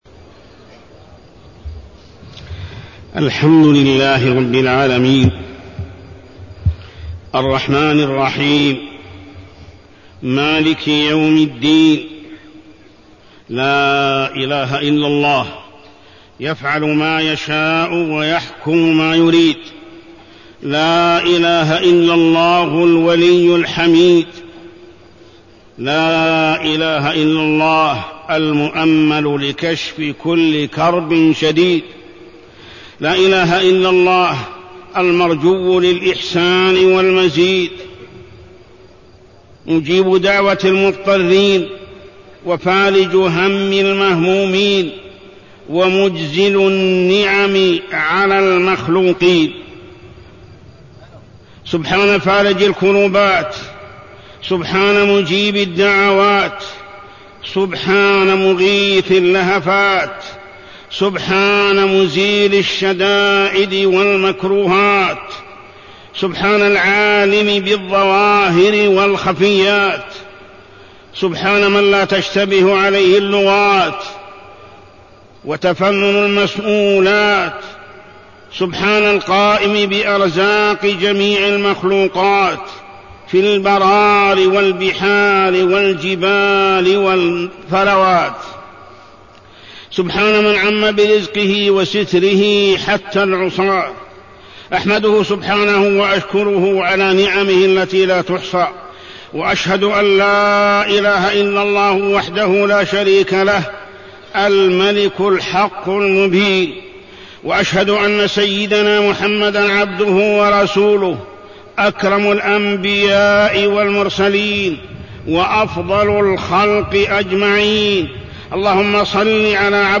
تاريخ النشر ١٤ ذو القعدة ١٤٢١ هـ المكان: المسجد الحرام الشيخ: محمد بن عبد الله السبيل محمد بن عبد الله السبيل الإستغفار من الذنوب